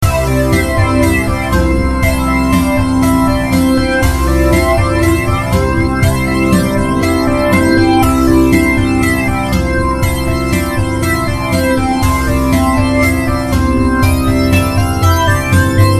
試聴用 MP3ファイル ループ再生になっておりますので、BGMなどの参考にしてください。
関連 ショート曲 …【 ネームセレクト01 】 POINT 波形編集をほどこしたところ。
BGM 明るい 普通